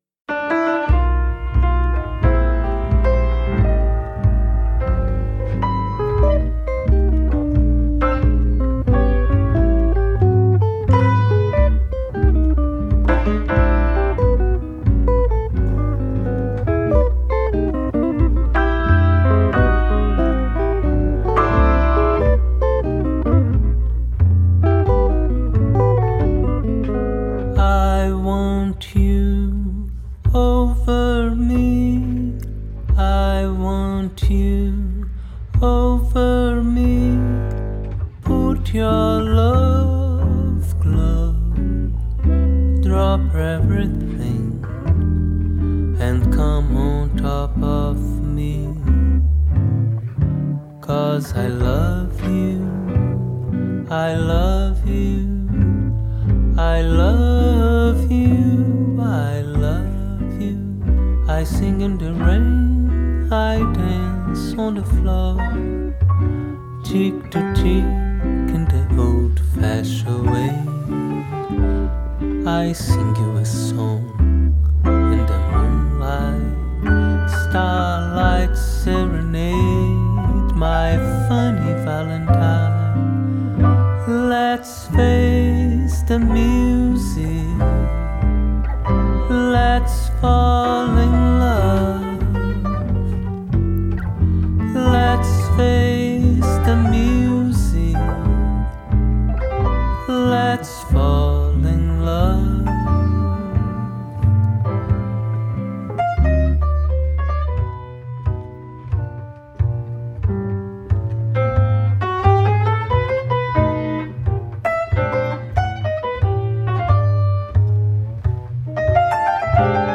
ボサノヴァ～叙情派サンバの流れを汲む傑作！